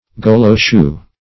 Goloe-shoe \Go*loe"-shoe`\, n.